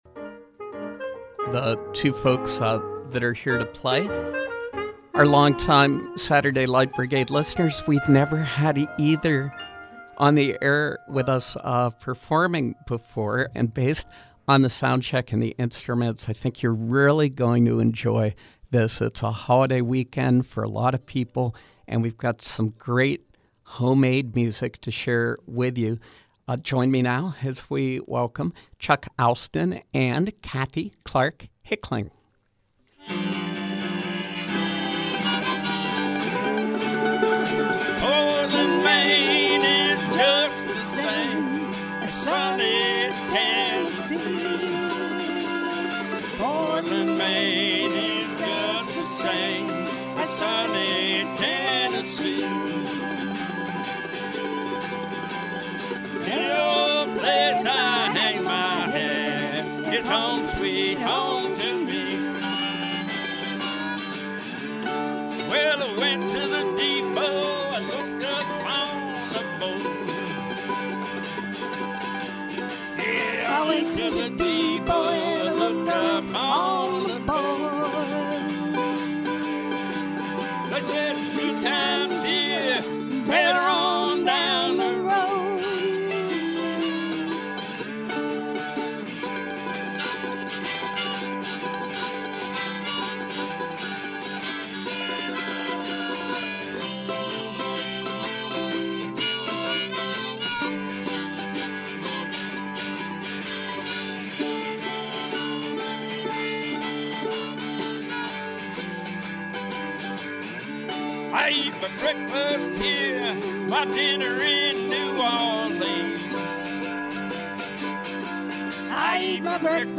Roots duo